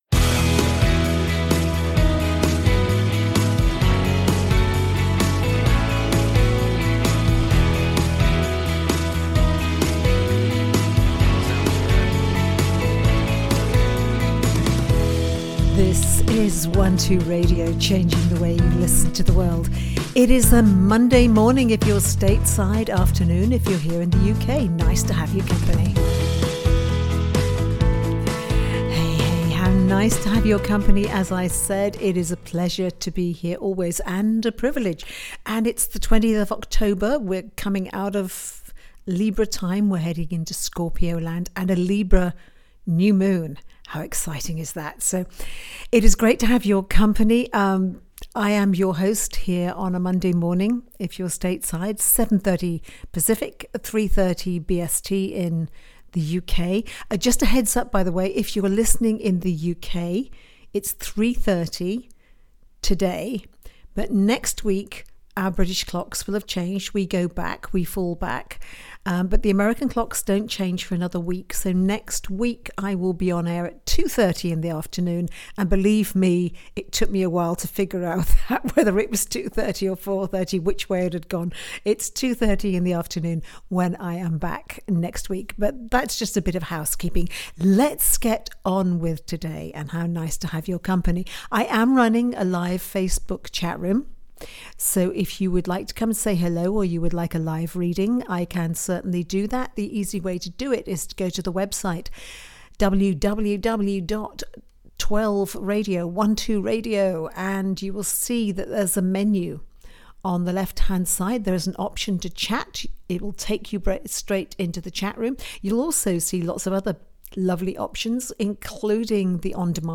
readings of the day